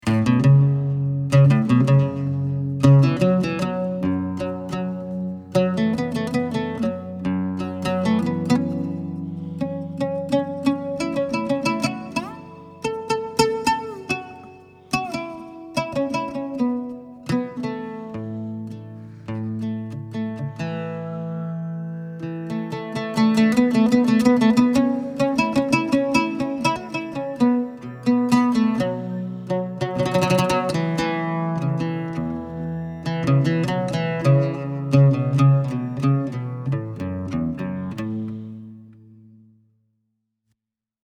Kopuz (Llaüt)
Kopuz o Llaüt: Instrument de punteig composat d’onze cordes; comunica un sentiment de dinamisme gràcies al seu volum de ressonància i la manera rítmica de ser tocat. L’única diferència entre el Kopuz i el Llaüt és que el primer té la caixa de ressonància una mica més petita i una pell de xai que la recobreix, fet que li dóna una sonoritat molt adecuada per a la musicoteràpia.
Taksim-Kopuz-Llaut.mp3